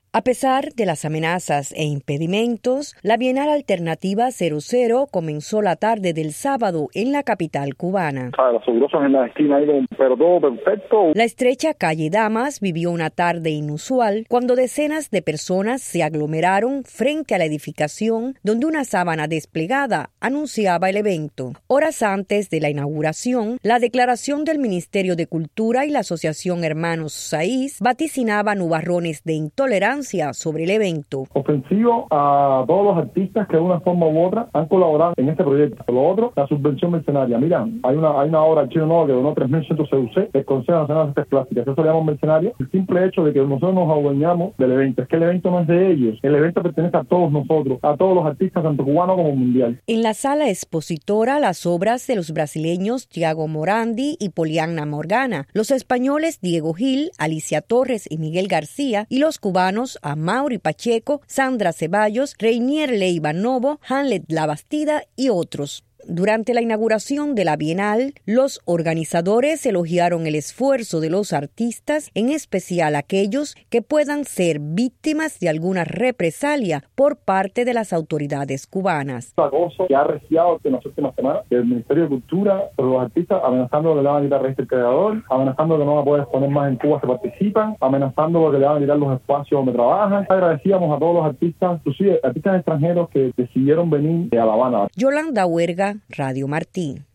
Declaraciones de Luis Manuel Otero a Radio Martí